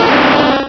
pokeemerald / sound / direct_sound_samples / cries / gengar.aif
-Replaced the Gen. 1 to 3 cries with BW2 rips.